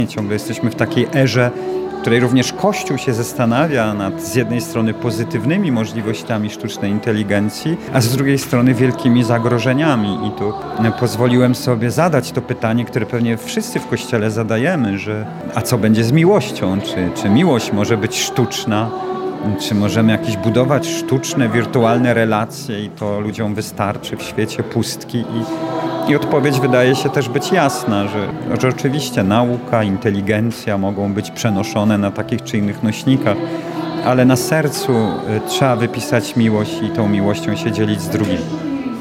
Jednym z prelegentów był biskup Andrzej Przybylski, przewodniczący Krajowej Rady Duszpasterstwa Powołań, który podkreślał , aby w dobie internetu i mediów społecznościowych nie możemy zapominać o tym, co dla nas najważniejsze.